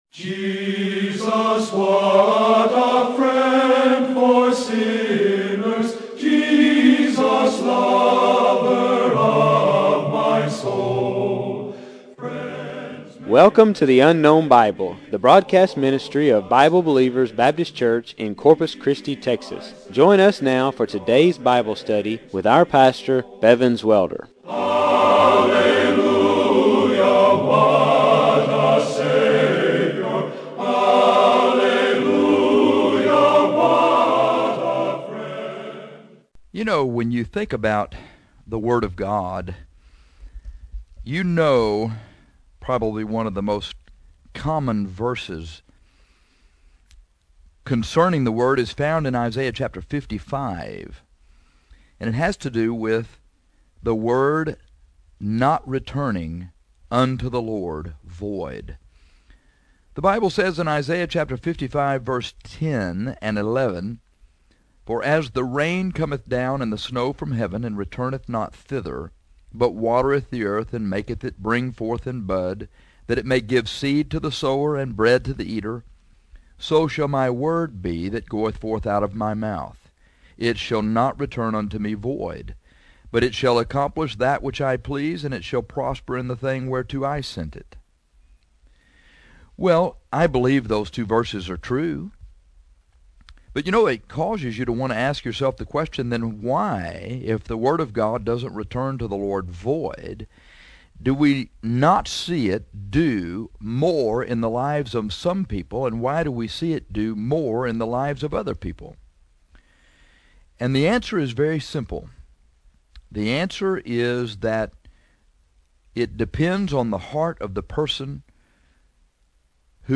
So, today we are going to preach on your heart and the word. We will be using the parable of the sower and the seed from Matthew 13:3-8, 18-23; Mark 4:3-8, 14-20; and Luke 8:5-8, 11-15 as our texts.